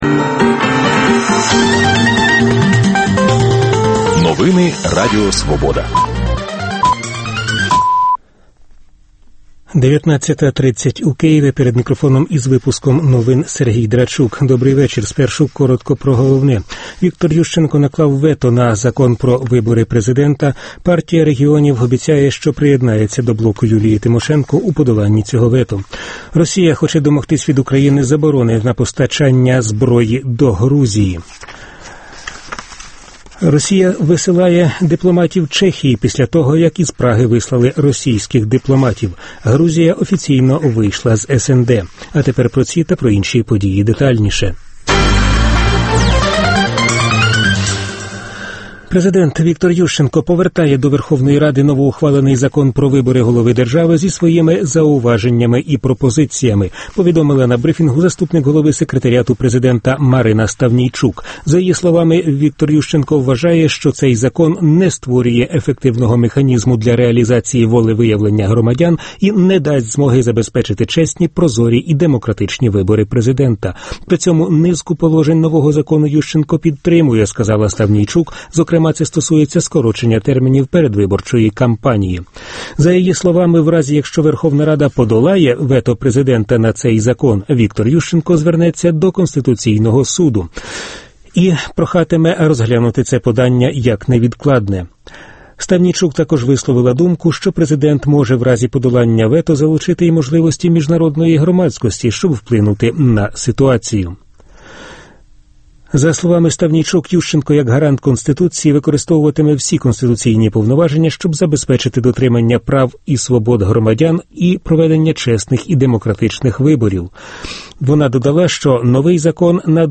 Дискусія про головну подію дня.